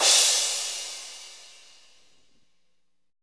ROCK CRSH.wav